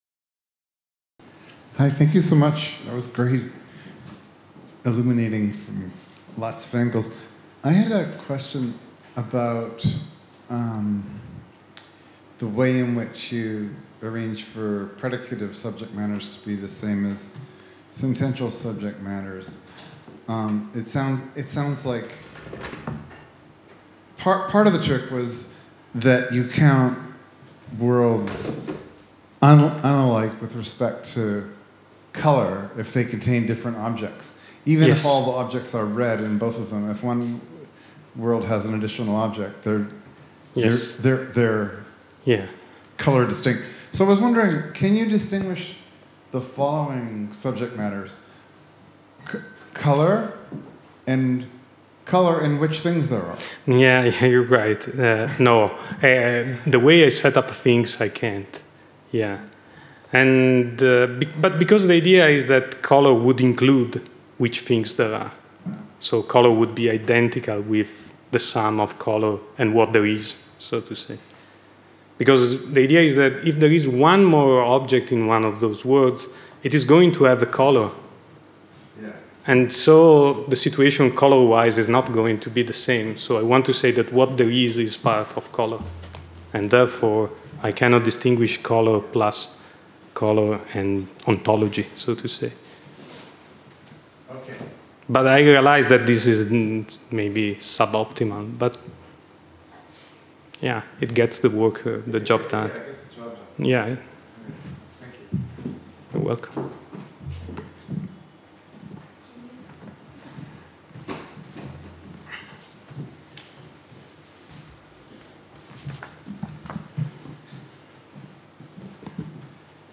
Discussion (3) | Collège de France